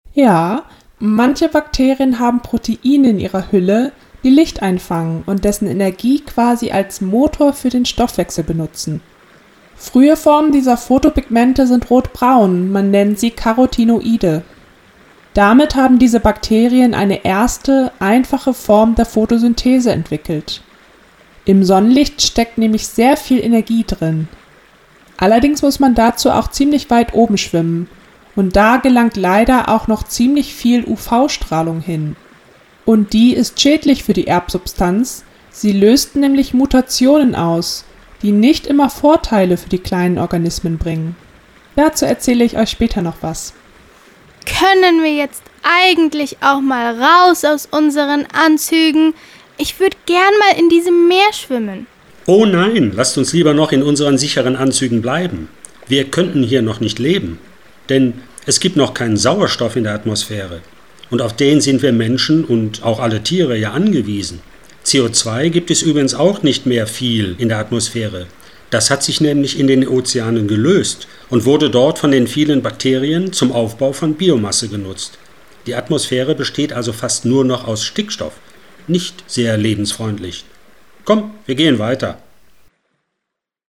Diese Station im Hörspiel: